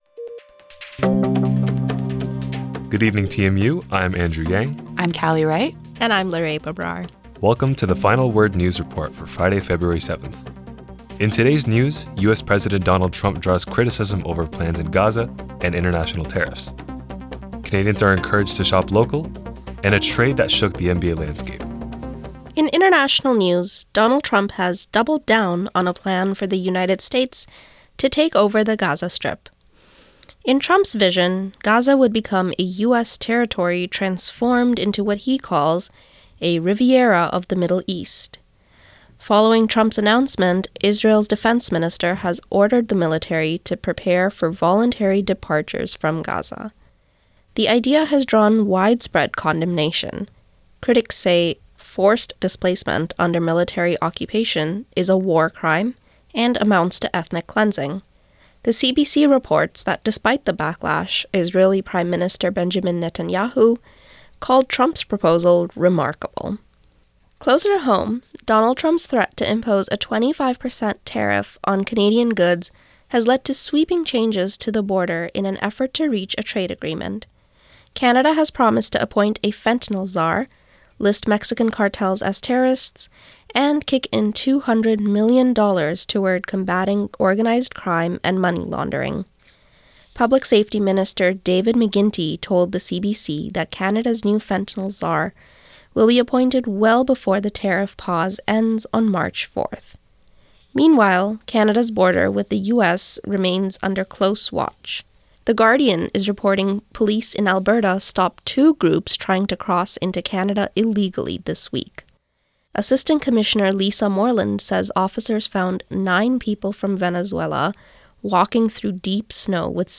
This is the Met Radio Newswrap produced for The Final Word on 1280 AM in Toronto,  Fridays at 5:30 pm, during the winter term 2025 by first-year graduate students in journalism at TMU.